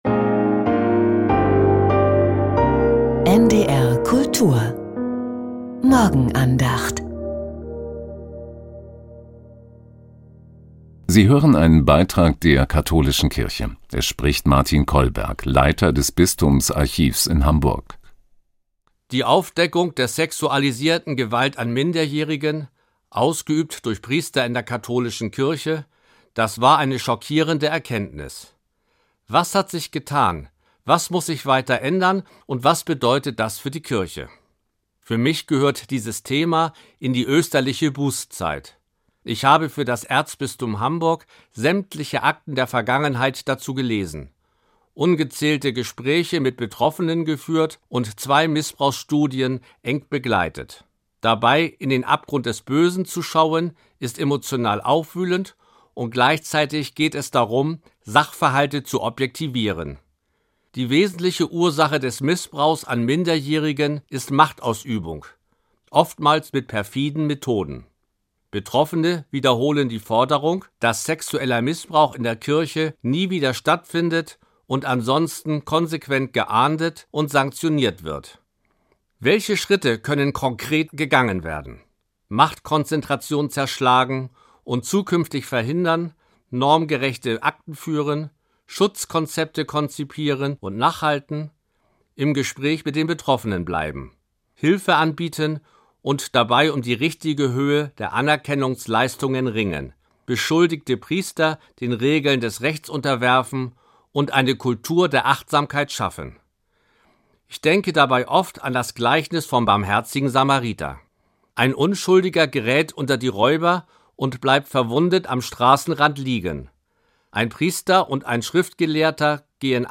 Aufarbeitung ~ Die Morgenandacht bei NDR Kultur Podcast